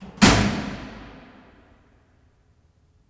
Heavy Switch (1).wav